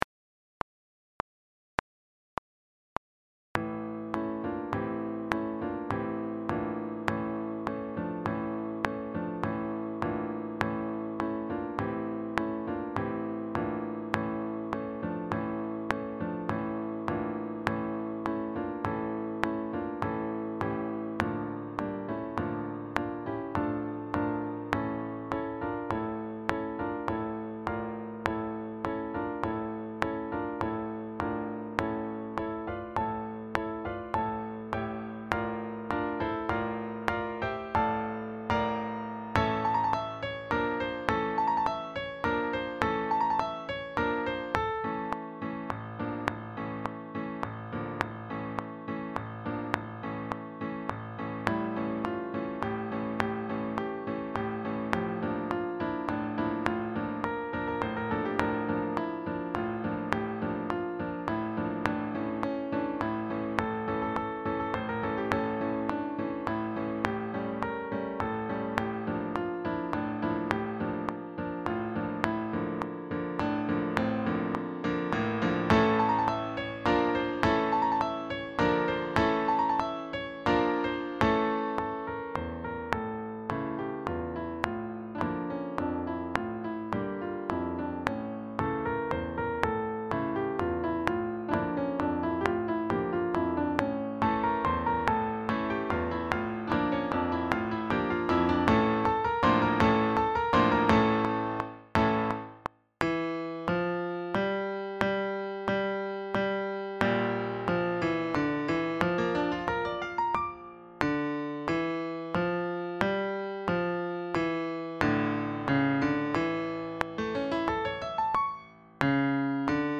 Sax Octets
SAAATTBbDuration:
Backing track